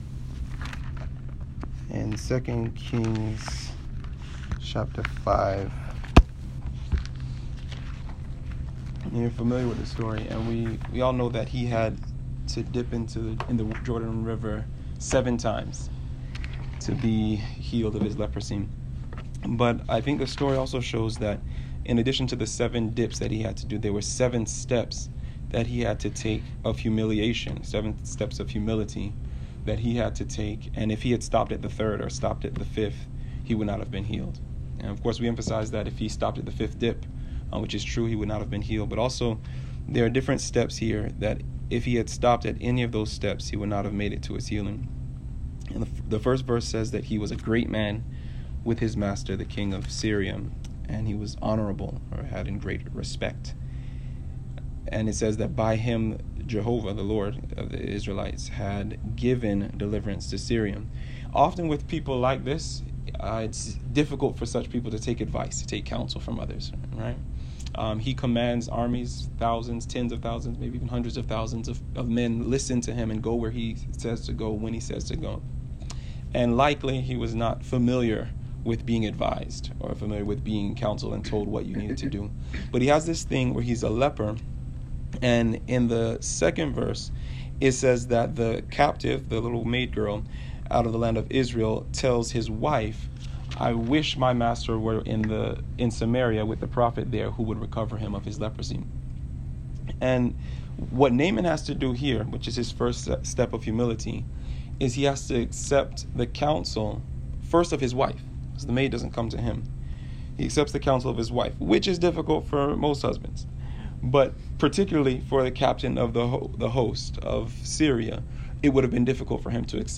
This presentation draws practical lessons from the experience of Naaman recorded in 2 Kings 5. It was originally presented to a small worship group at the headquarters of the Seventh-day Adventist Church.